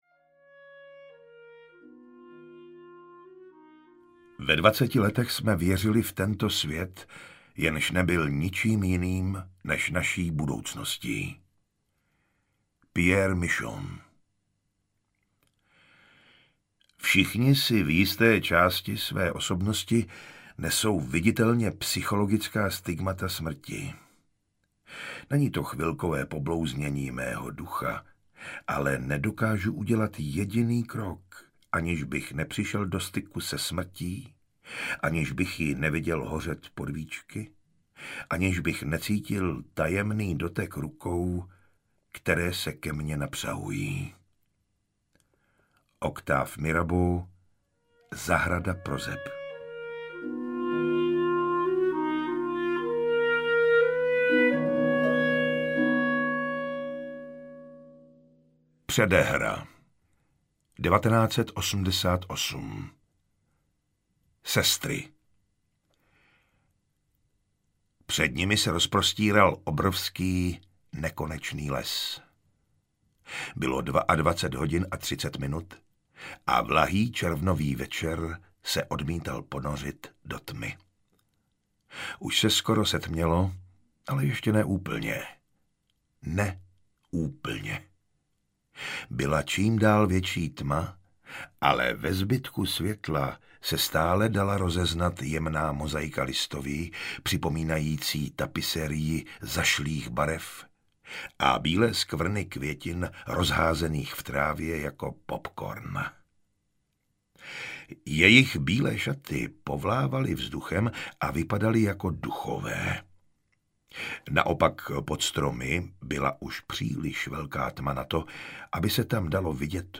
Sestry audiokniha
Ukázka z knihy